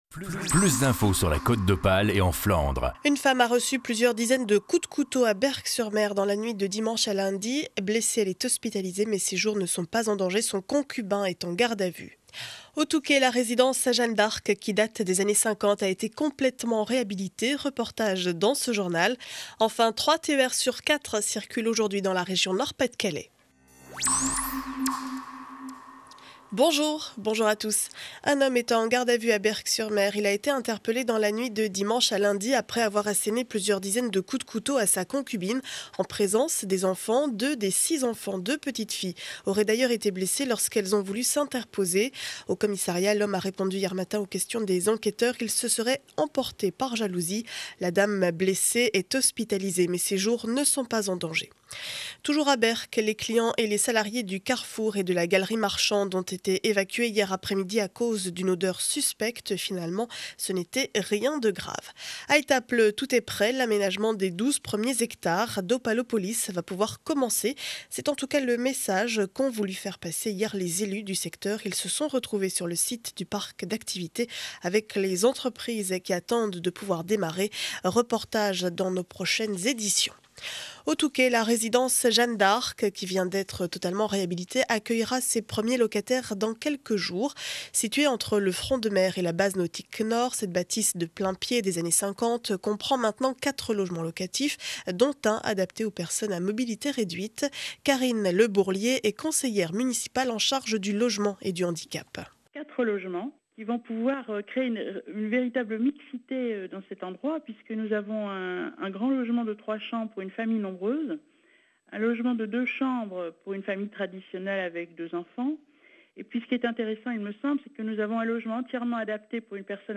Journal du mardi 7 février 2012 7 heures 30 édition du Montreuillois.